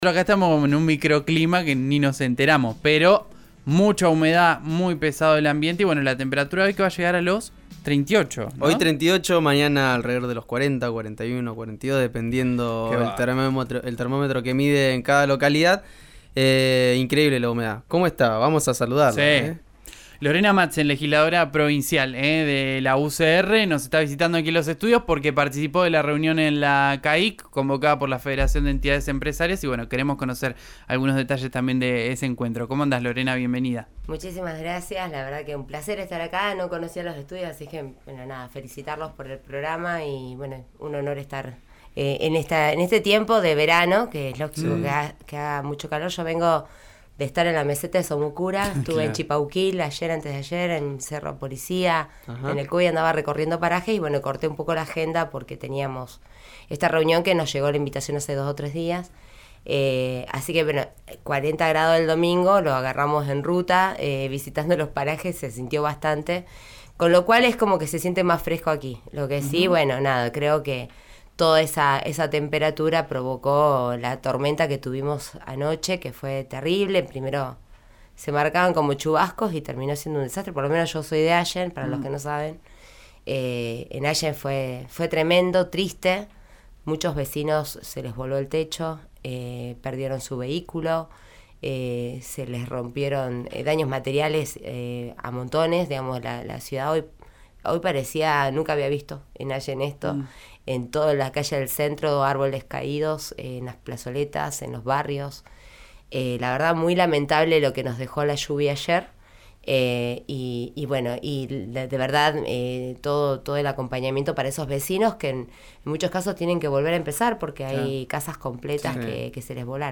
Escuchá a Lorena Matzen en RÍO NEGRO RADIO: